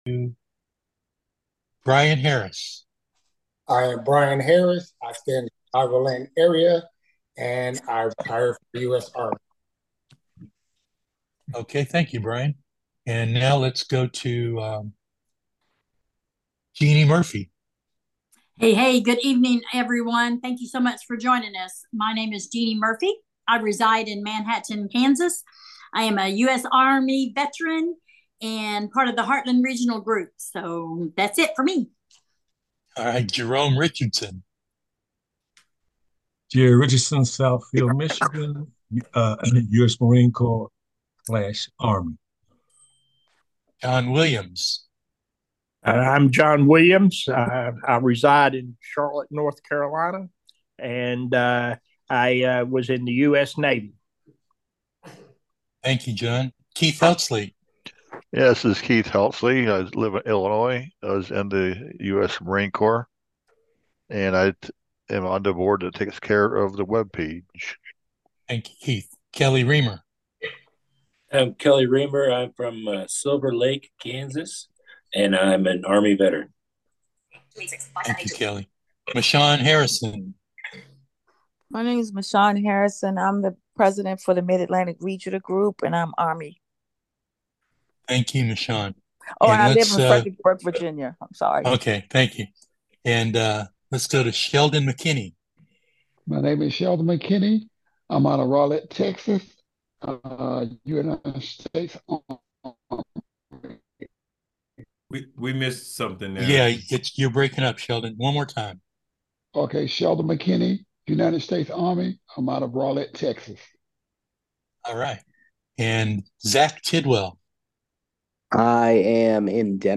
after our Roll call, we get started.